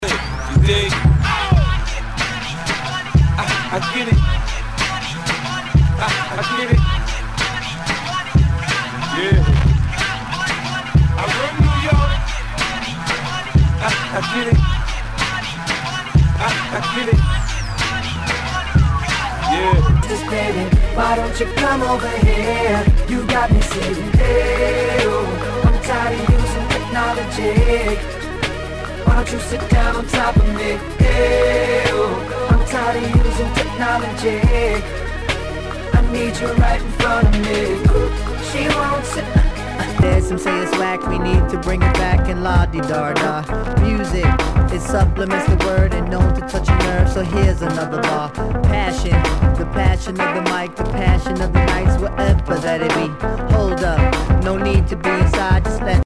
ナイス！ヒップホップ！
全体にチリノイズが入ります